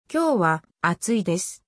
Pronunciation : kyō wa atsui desu